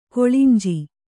♪ koḷinji